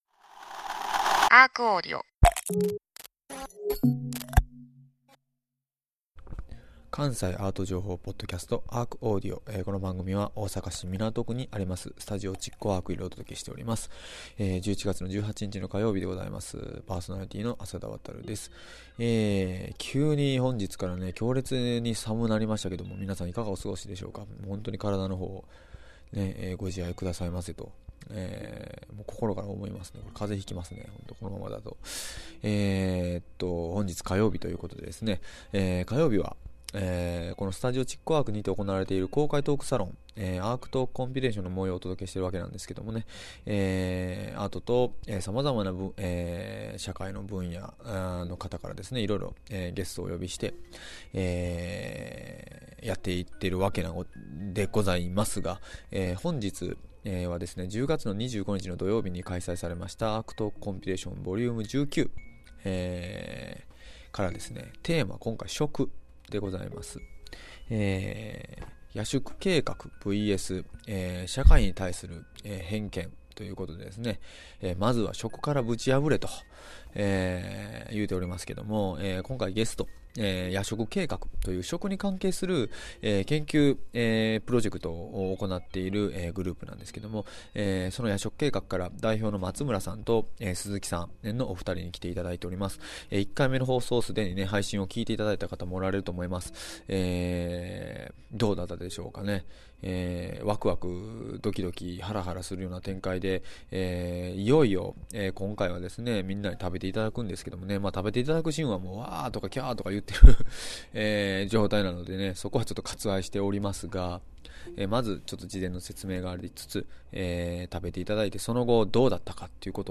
毎週火曜日は築港ARCにて毎月開催されるアートと社会を繋ぐトークイベント「ARCトークコンピレーション」の模様を全4回に分けてお届けします。
2回目の配信では、いよいよ試食会開始！といってもその箇所は音声では割愛してますので、写真で雰囲気をお楽しみください。試食のあとは、いよいよ食に関するディスカッション開始！